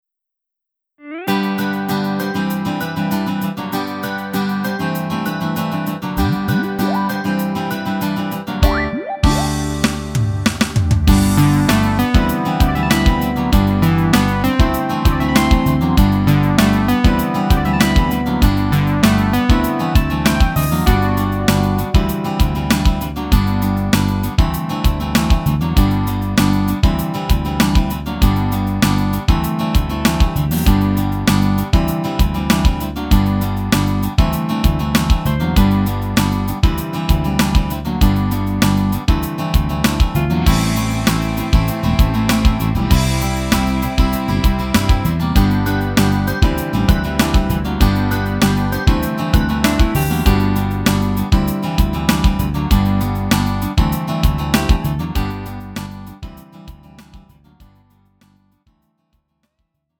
음정 원키 4:01
장르 pop 구분 Lite MR